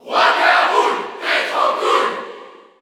Category: Crowd cheers (SSBU) You cannot overwrite this file.
King_K._Rool_Cheer_French_NTSC_SSBU.ogg